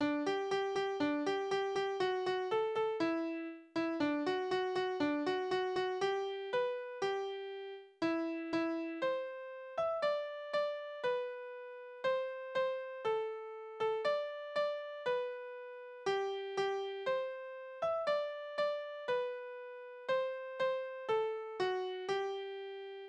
Kinderlieder: Bub und Spinne
Tonart: G-Dur
Taktart: 2/4
Tonumfang: große None